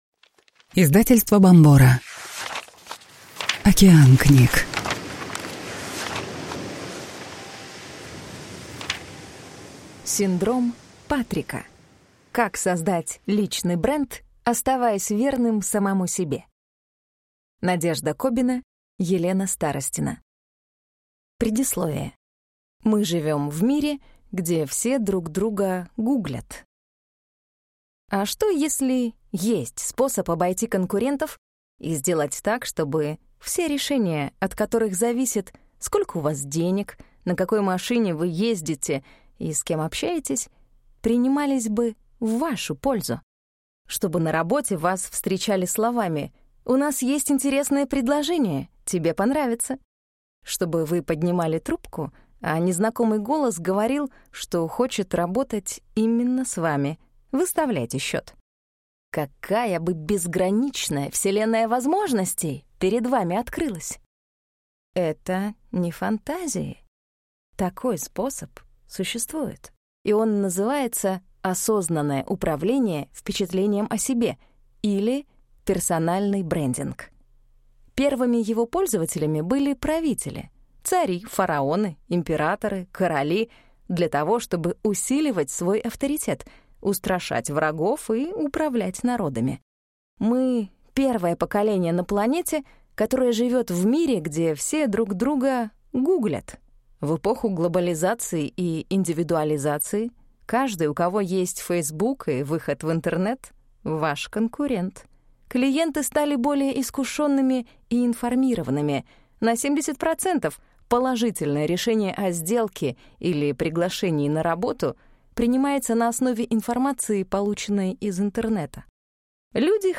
Аудиокнига Синдром Патрика. Как создать личный бренд, оставаясь верным самому себе | Библиотека аудиокниг